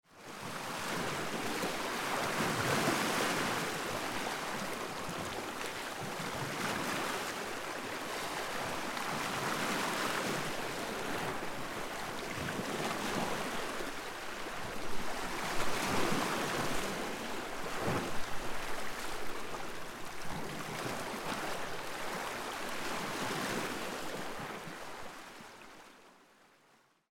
フィールドレコーディングレポート Vol.3 しまなみ海道
今回収録した島は向島と因島で、メインは波の音となります。
今回は、Tascam のPortacaputure X8 というフィールドレコーダーで録音しました。
今回は波の音を録音してきました。
それはともかく、↓がほぼ無風だった因島での音源になるので、是非ご視聴ください！